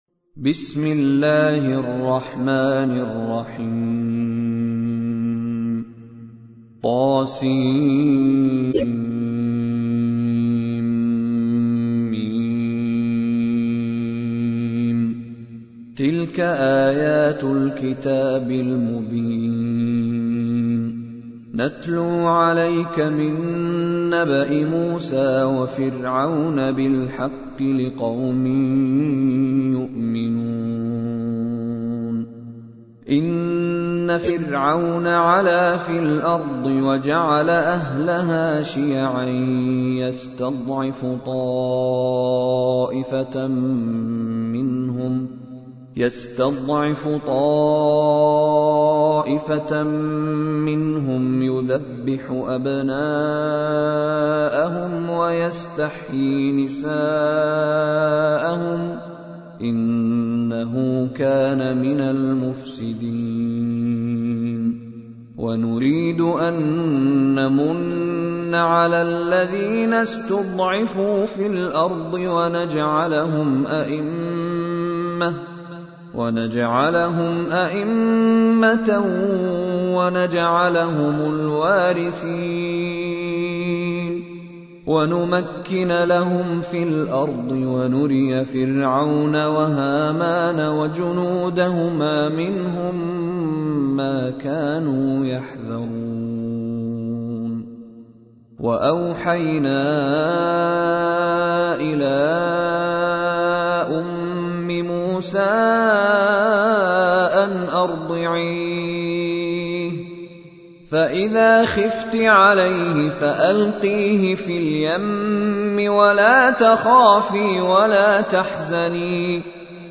تحميل : 28. سورة القصص / القارئ مشاري راشد العفاسي / القرآن الكريم / موقع يا حسين